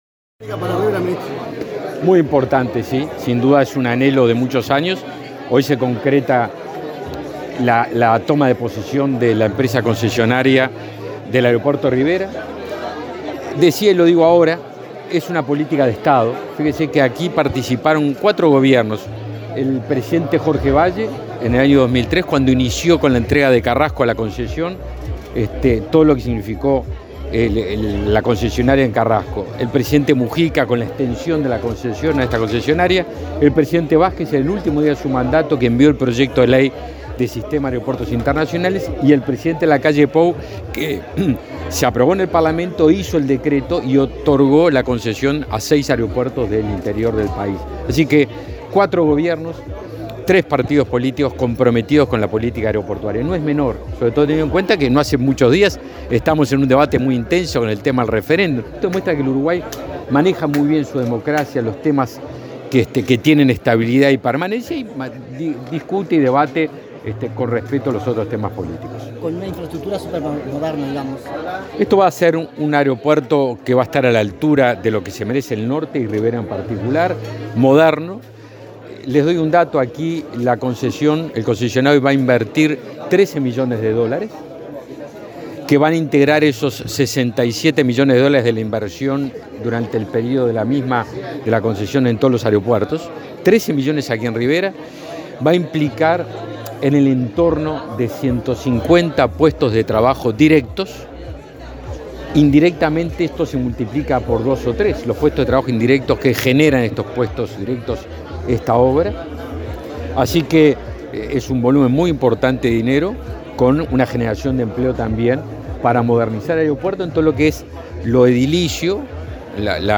Declaraciones a la prensa del ministro de Defensa Nacional, Javier García
El ministro de Defensa Nacional, Javier García, participó este viernes 22 en el acto en el que la empresa Corporación América Airports se hizo cargo